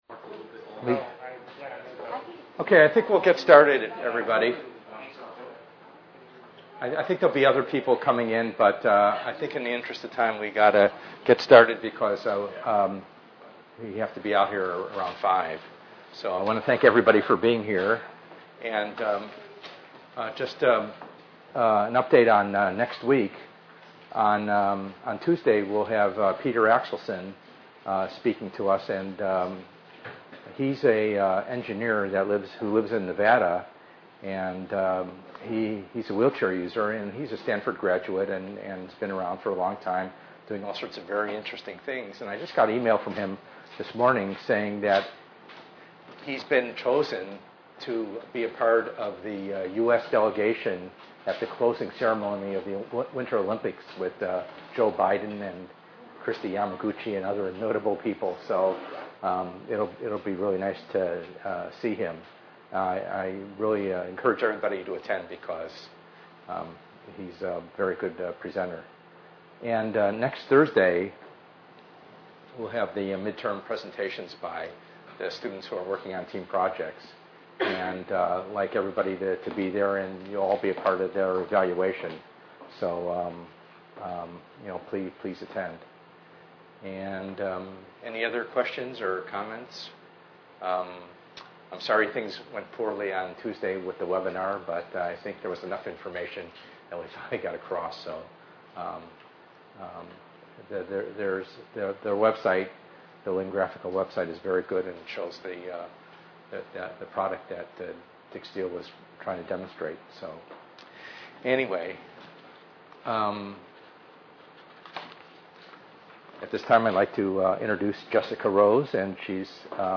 ENGR110/210: Perspectives in Assistive Technology - Lecture 5b